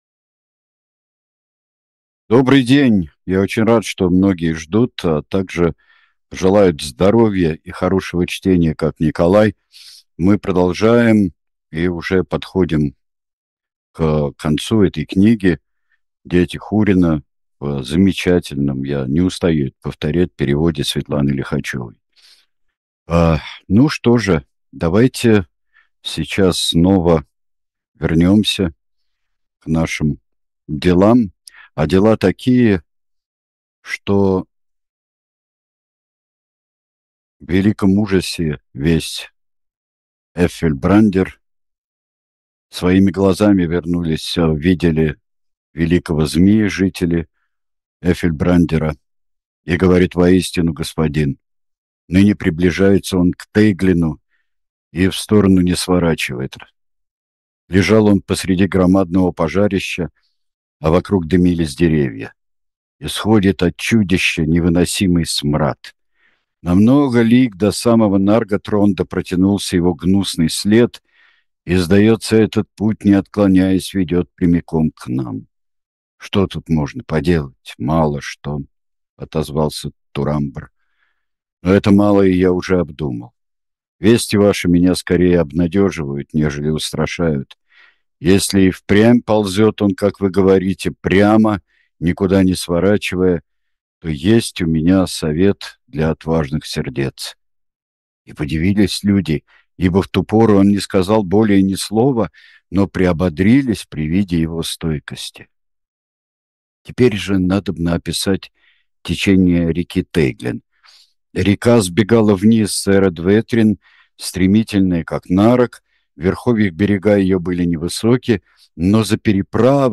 Читает Сергей Бунтман.